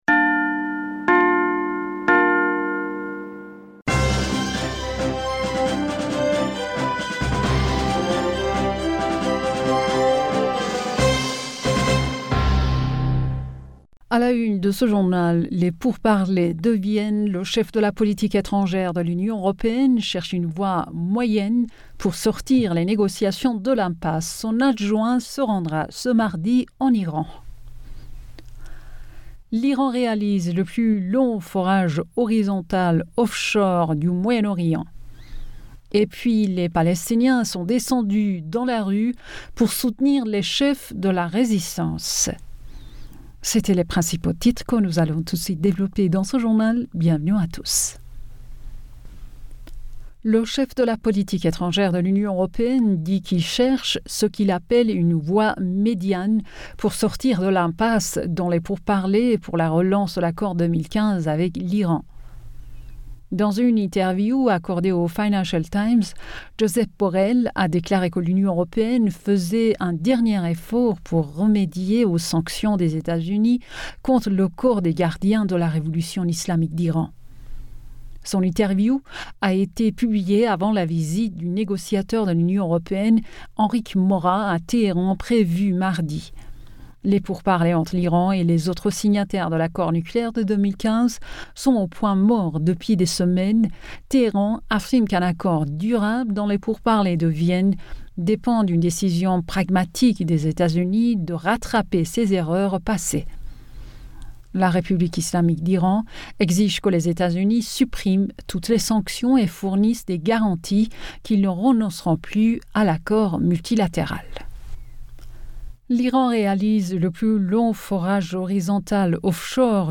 Bulletin d'information Du 08 Mai 2022